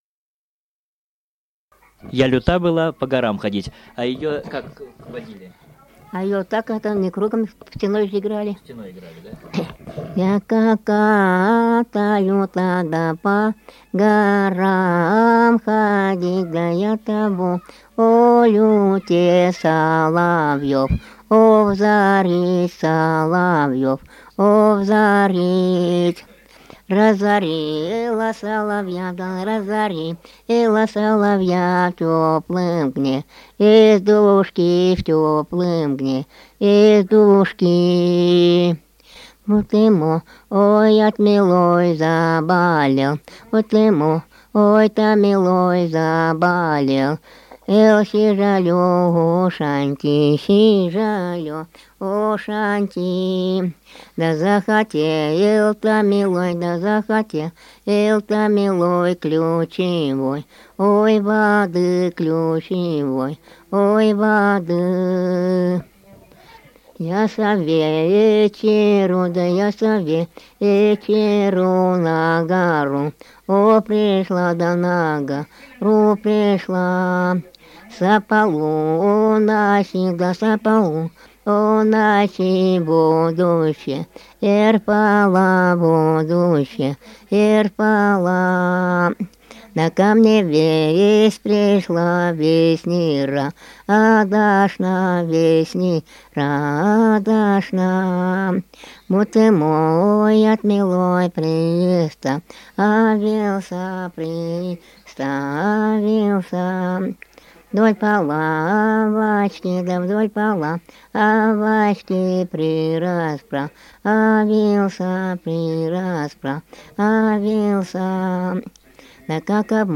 Республика Алтай, Усть-Коксинский район, с. Верхний Уймон, июнь 1980.